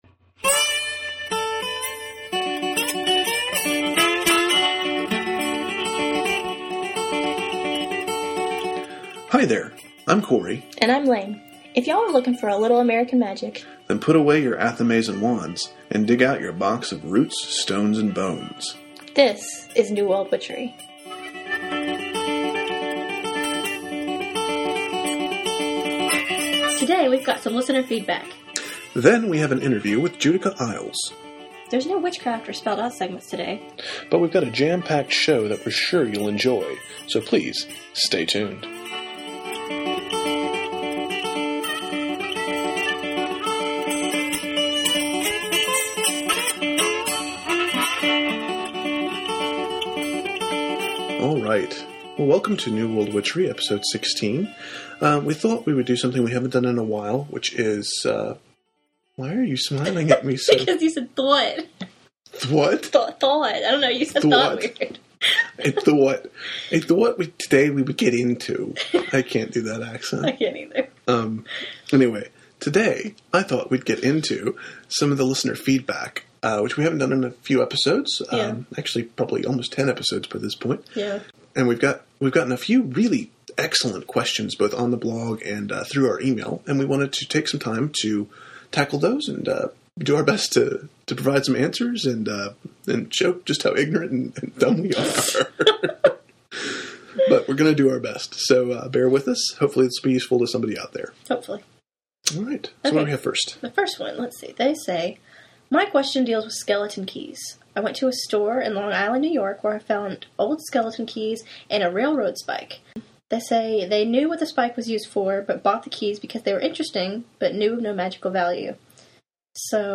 -SHOWNOTES FOR EPISODE 16- Summary Today we answer some listener questions and present some feedback. Then we have an interview